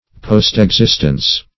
Postexistence \Post`ex*ist"ence\, n. Subsequent existence.